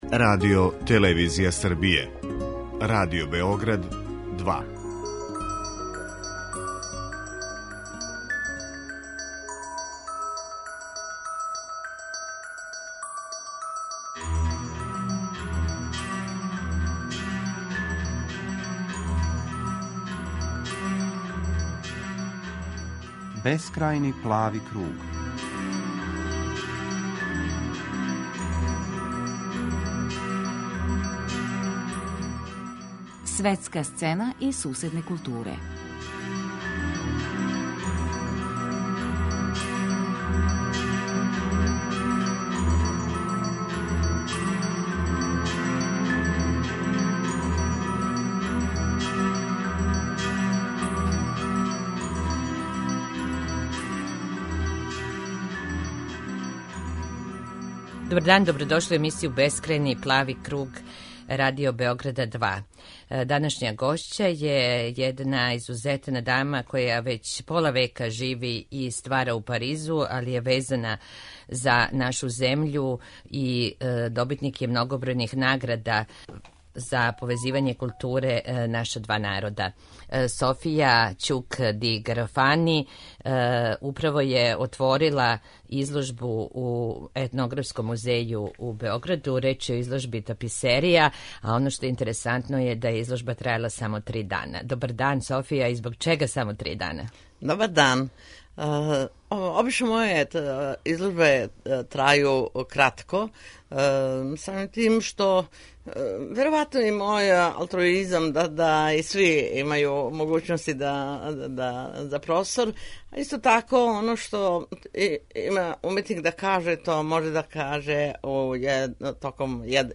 Гошћа